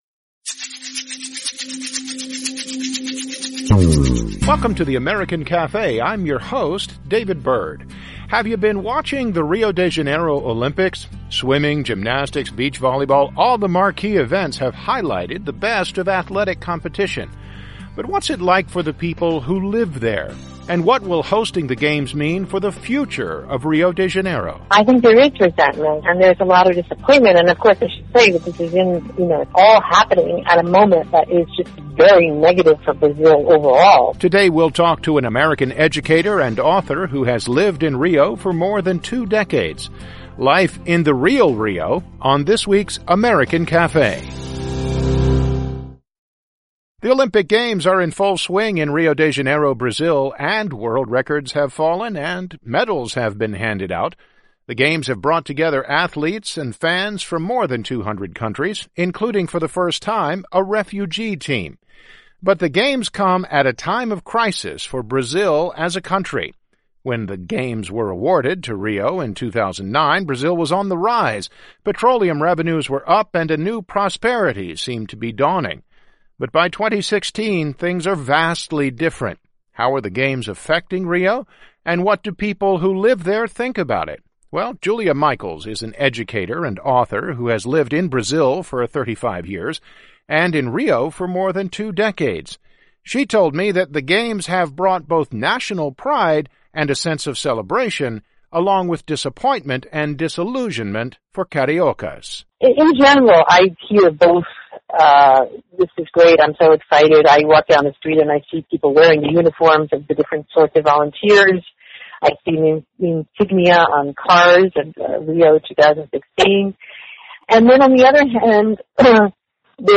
talked with an educator and author who has lived in Rio for more than two decades